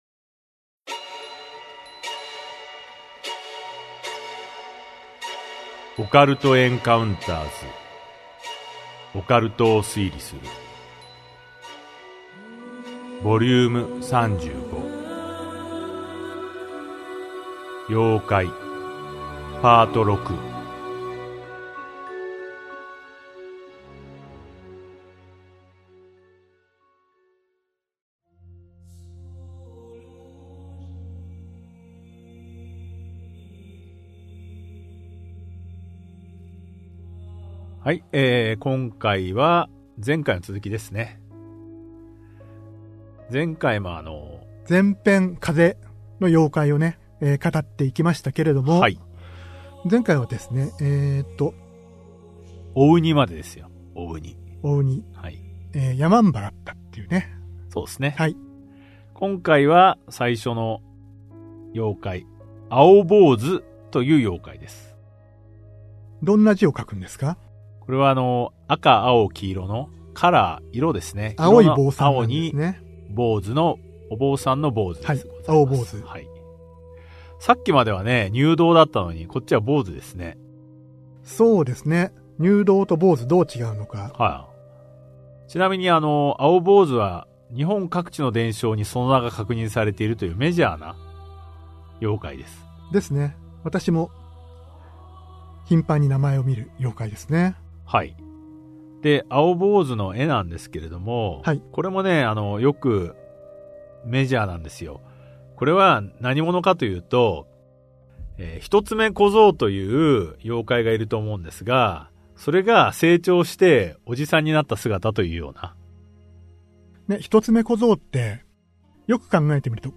[オーディオブック] オカルト・エンカウンターズ オカルトを推理する Vol.35 妖怪 6
オカルト・エンカウンターズの二人が伝承と文献を紐解き、伝説の裏側を推理する──。